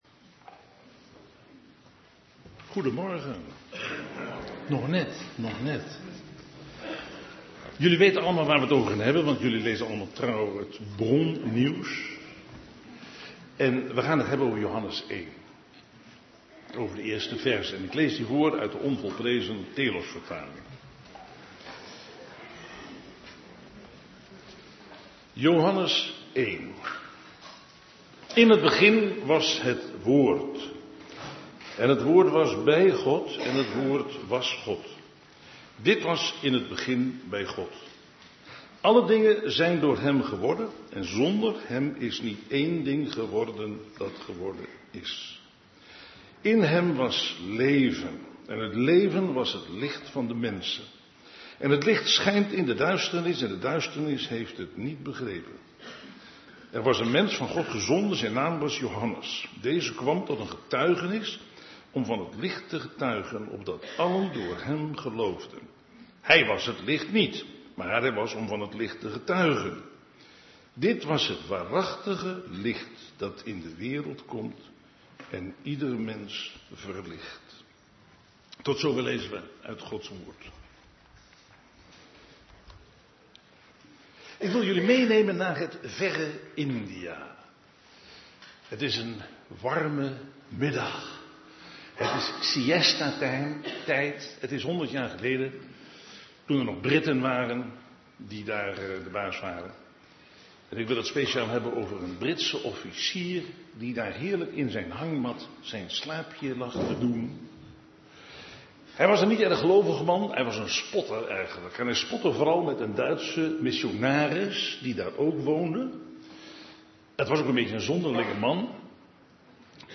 Toespraak van 8 december: : ‘In Hem was leven en het leven was het licht der mensen' - De Bron Eindhoven